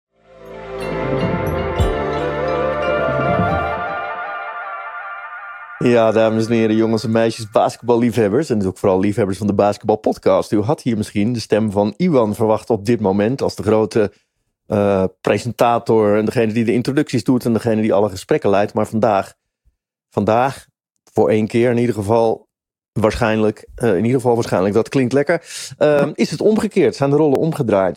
I have a friendly, knowledgeable, dynamic and/or emotional voice, but I can strike a lot of other tones as well.
- Soundproof home studio
Baritone